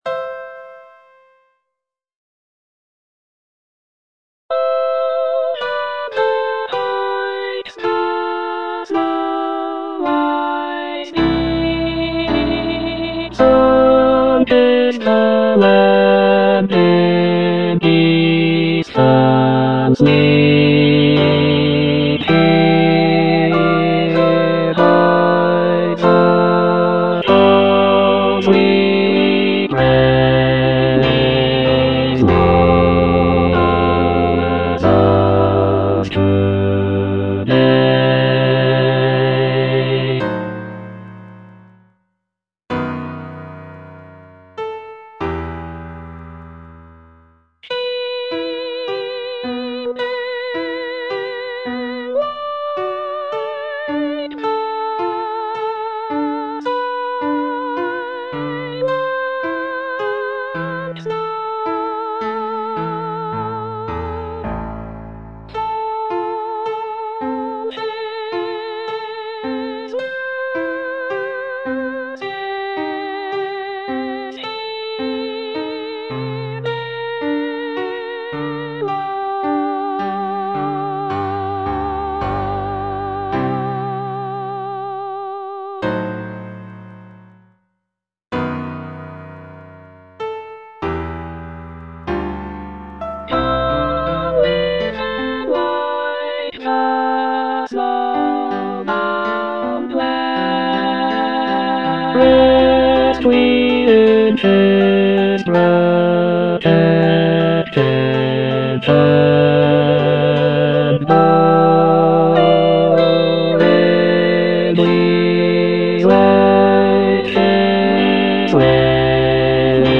(bass II) (Emphasised voice and other voices) Ads stop